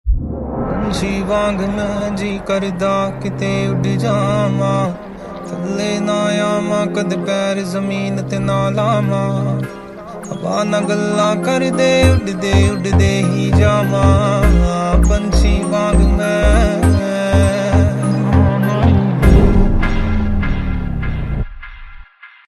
soulful and melodic